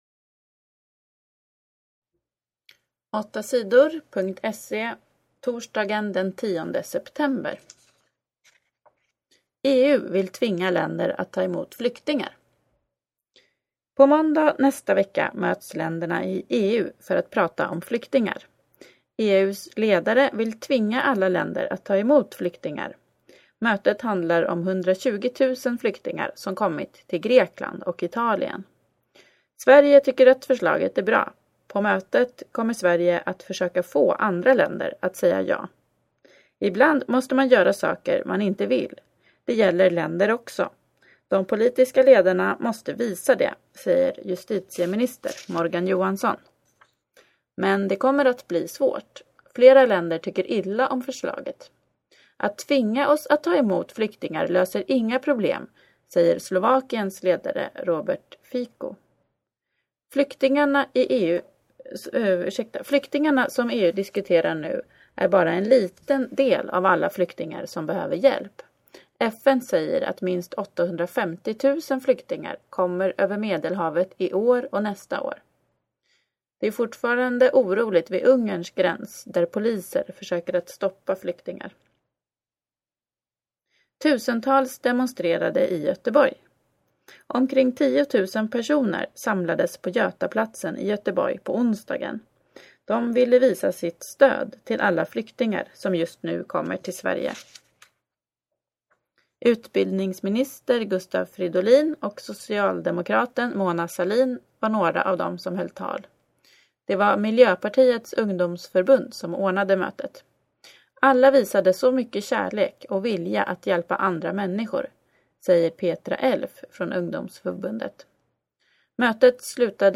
Lyssna på nyheter den 10 september